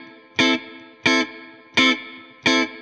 DD_StratChop_85-Amin.wav